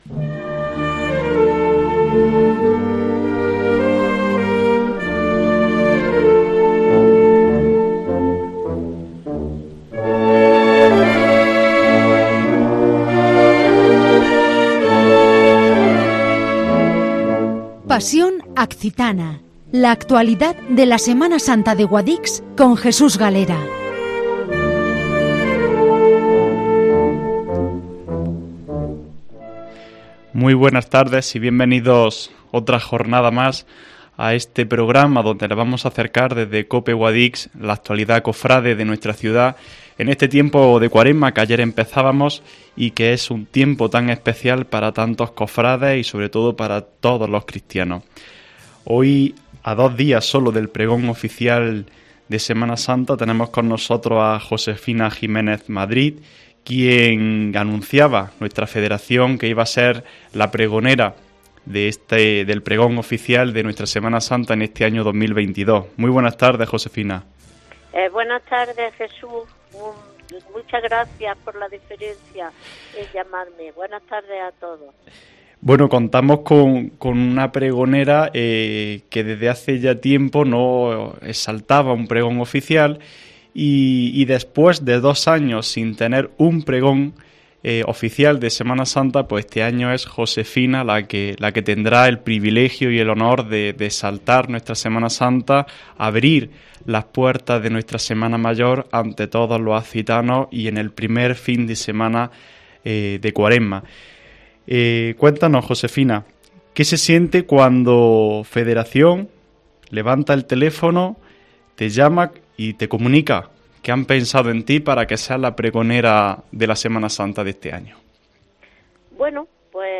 Guadix Pasión Accitana Entrevista